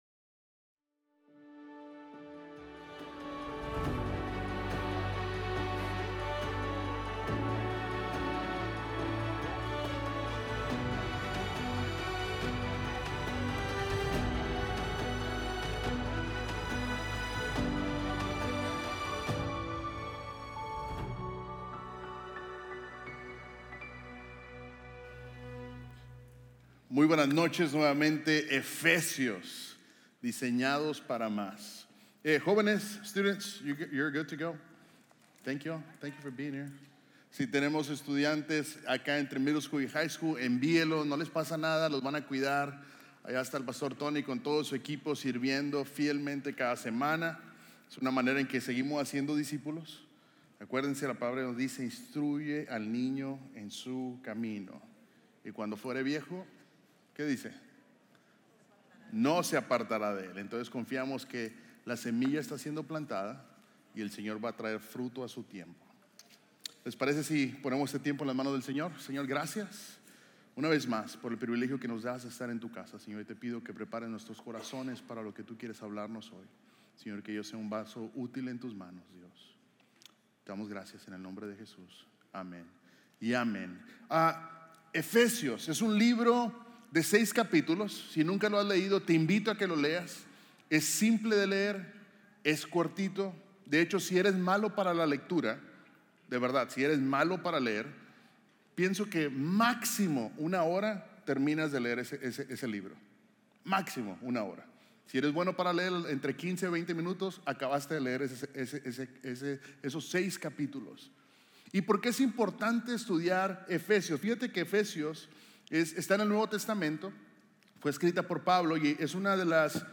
Series de Sermones – Media Player